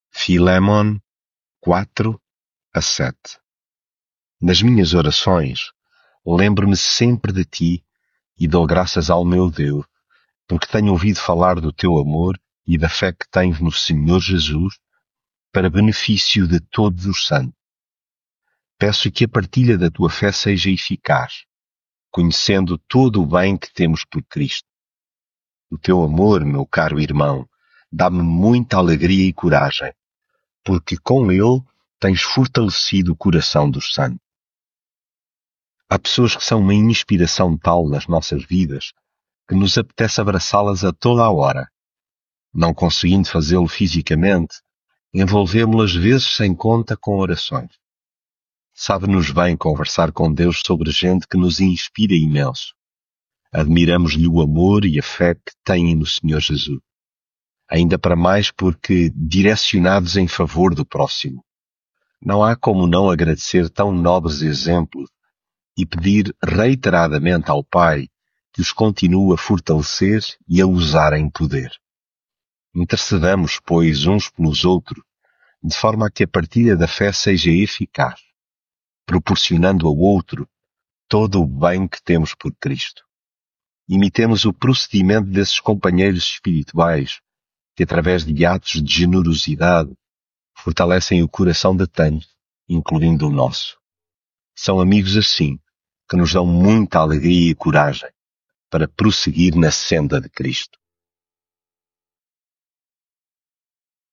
devocional filémon
leitura bíblica